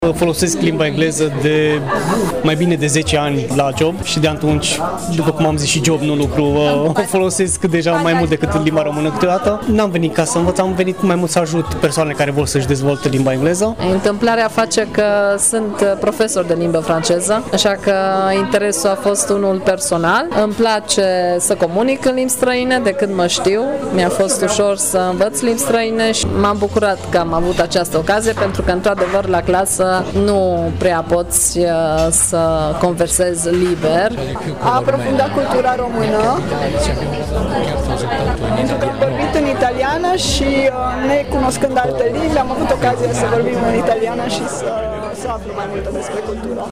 În cadrul evenimentului care a avut loc într-o cafenea din Târgu Mureș au fost organizate mai multe mese la care s-au purtat discuții pe diverse teme în limbile engleză, franceză și italiană.
Unii au venit să ajute, alții au venit să converseze, iar alții să cunoască cultura română, spun participanții: